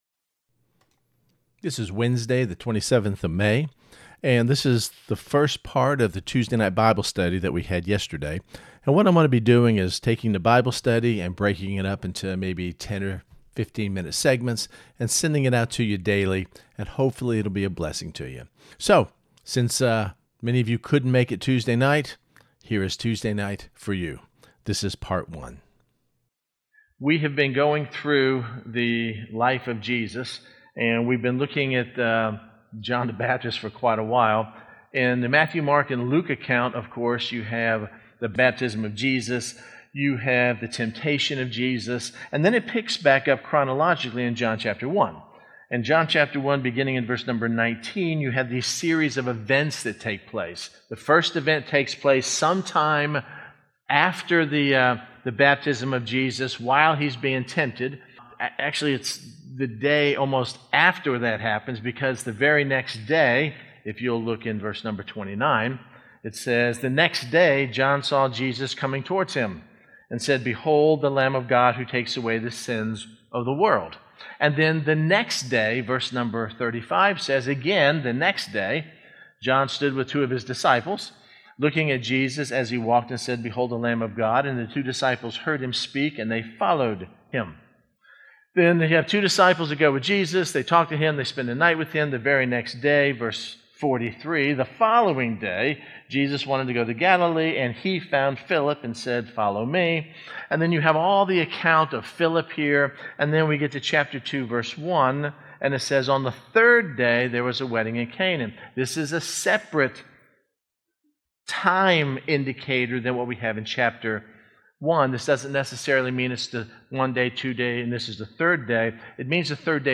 And since many of you are unable to attend on Tuesday, I have taken our study and broken it up into 5 sections of about 10 minutes each that I will send to you daily, beginning on the Wednesday following the study – which would be today.